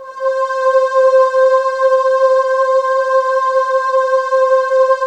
Index of /90_sSampleCDs/USB Soundscan vol.28 - Choir Acoustic & Synth [AKAI] 1CD/Partition C/10-HOOOOOO
HOOOOOO C4-R.wav